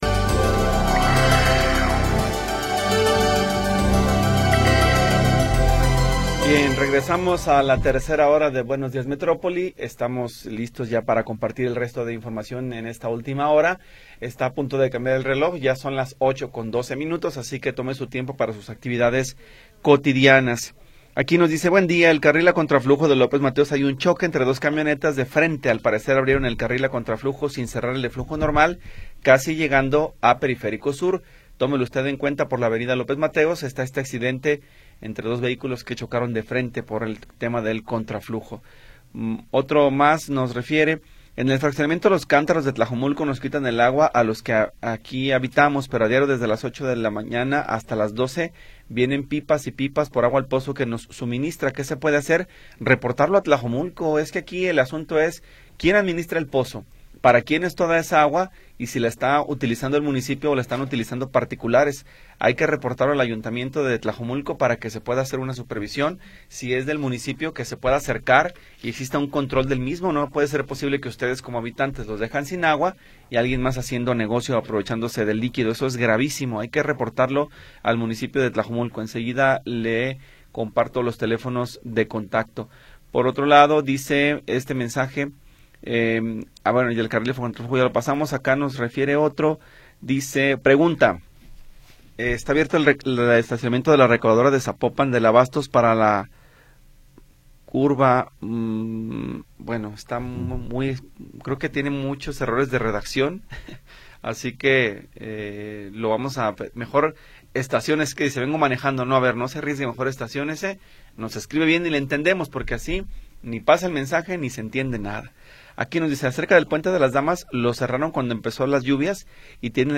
Tercera hora del programa transmitido el 28 de Noviembre de 2025.